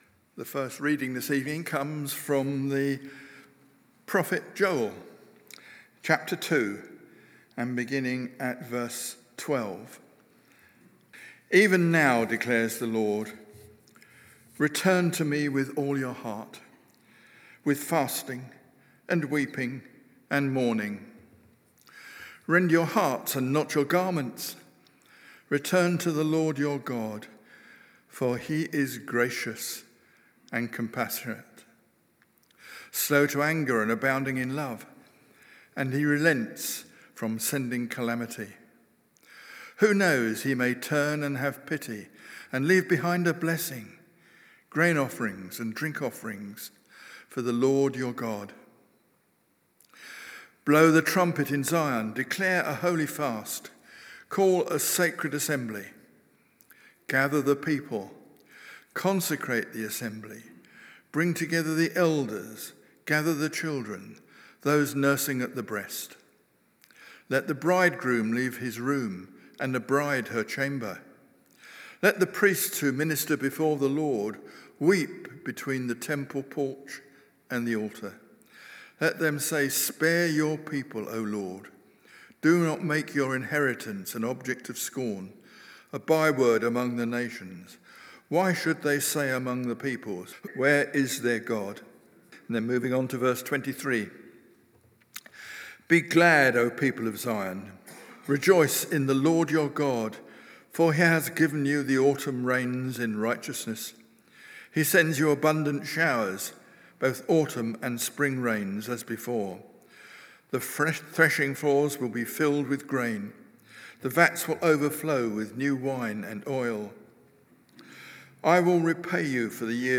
Emmanuel Church Sermons